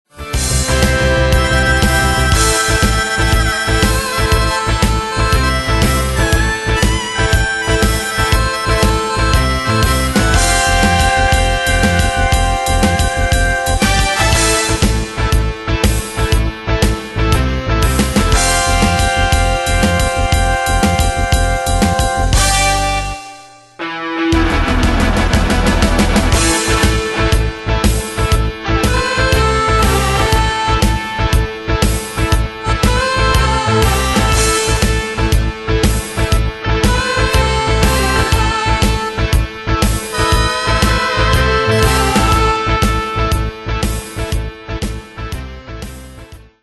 Demos Midi Audio
Danse/Dance: Rock Cat Id.
Pro Backing Tracks